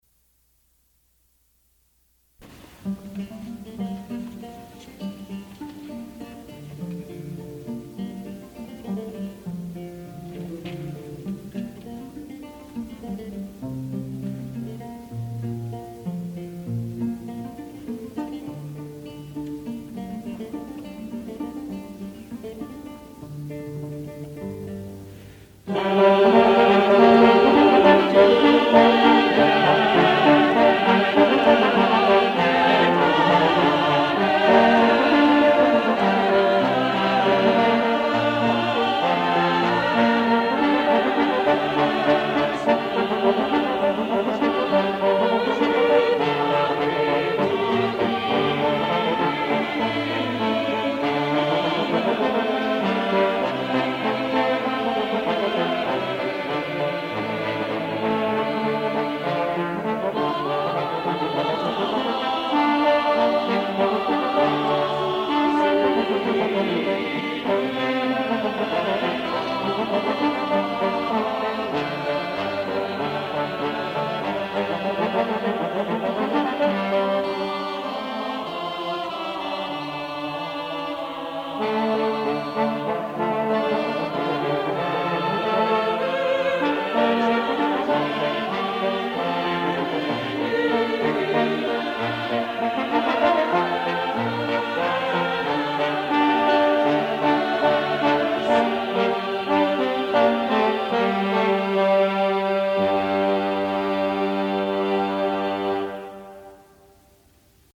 Borrowing the tenor and superius from Hayne van Ghizeghem, Josquin superimposes a unison canon at one beat separation to remarkable effect.